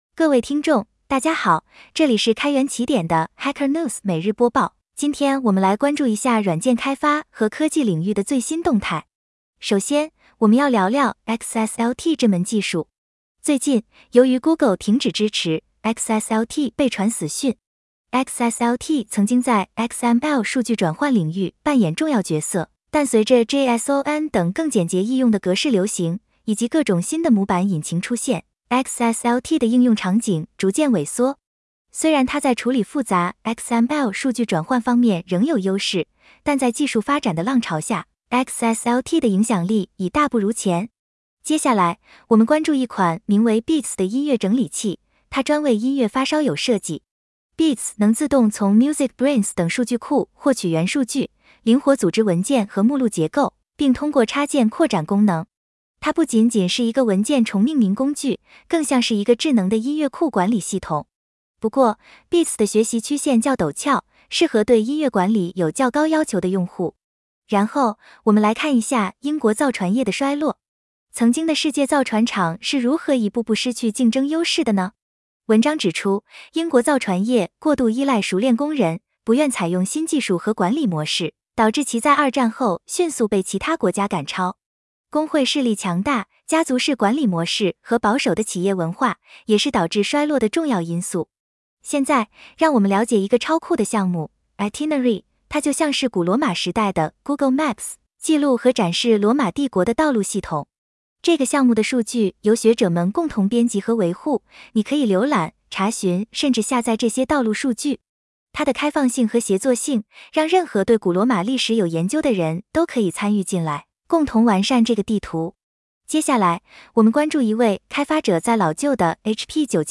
一个基于 AI 的 Hacker News 中文播客项目，每天自动抓取 Hacker News 热门文章，通过 AI 生成中文总结并转换为播客内容。